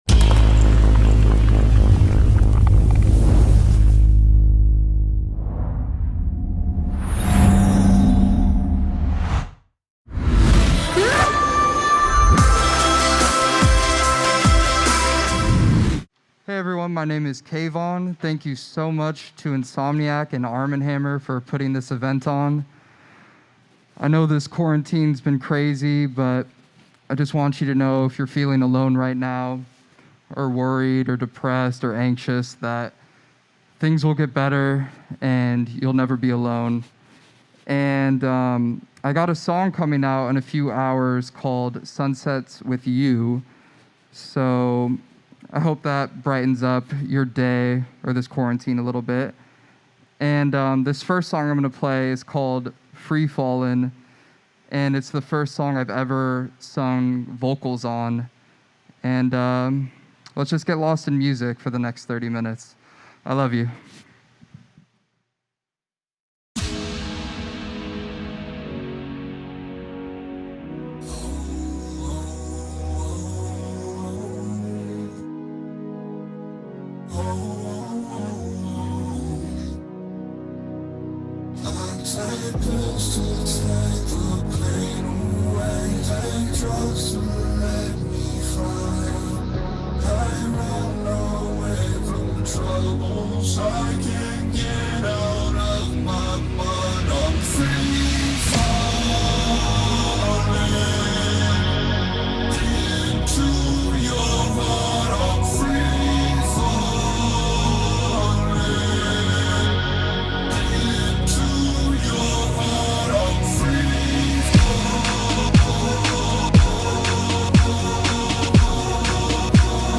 Genre: Dubstep